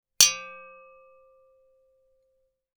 Metal_94.wav